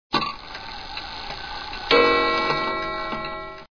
Index of /alarms
bigben.wav